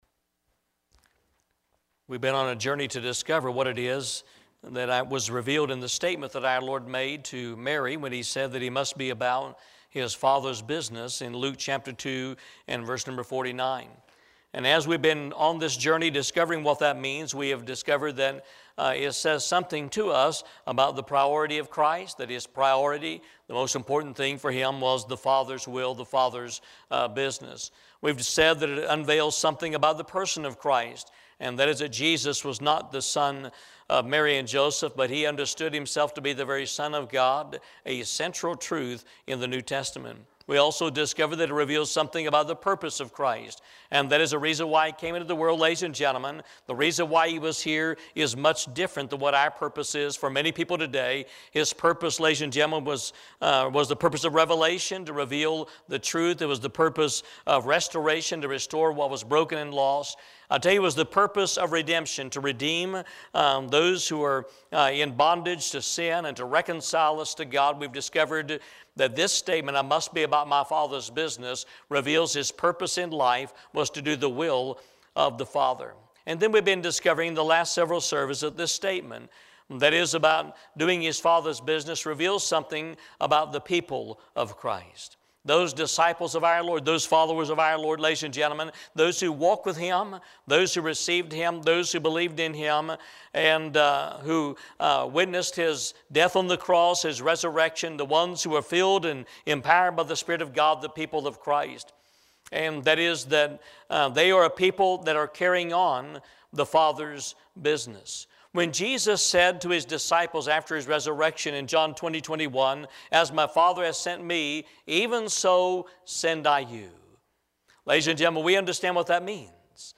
Learn more about this area of discipleship in tonight's message.